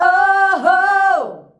OOOHOO  G.wav